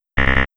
Error Sound.wav